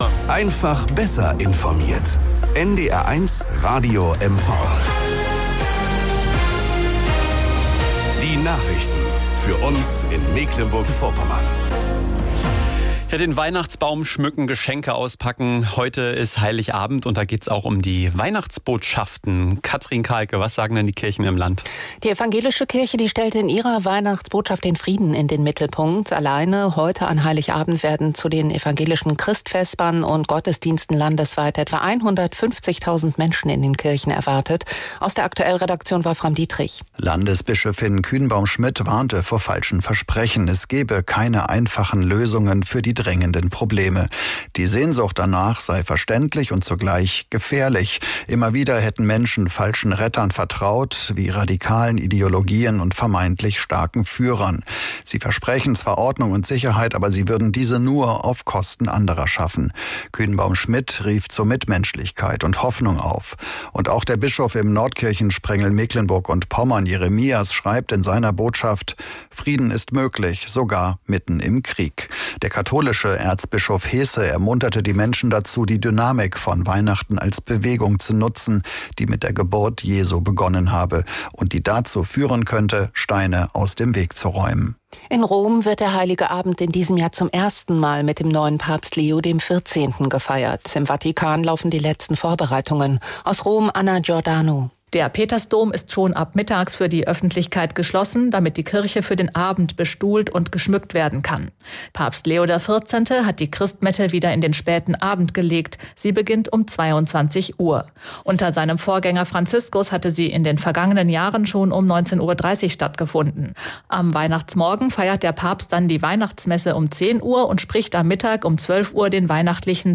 Nachrichten und Informationen aus Mecklenburg-Vorpommern, Deutschland und der Welt von NDR 1 Radio MV.